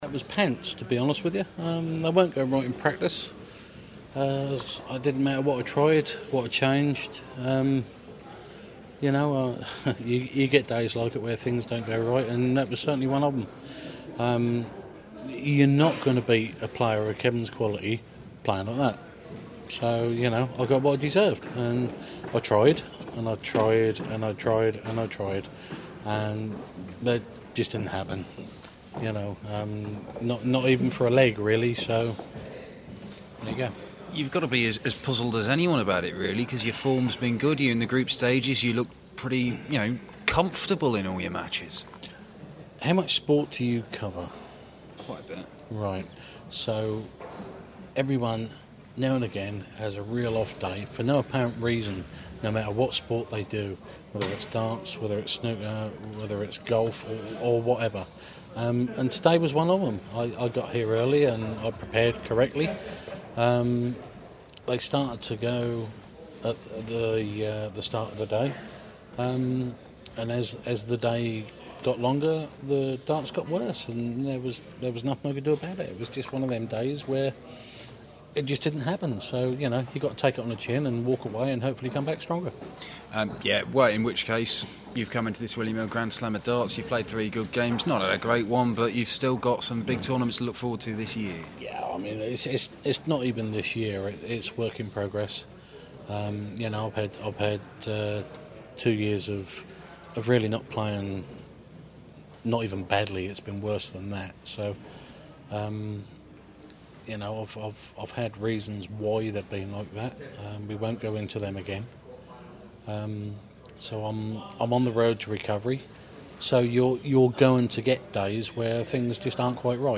William Hill GSOD - King Interview (Last 16)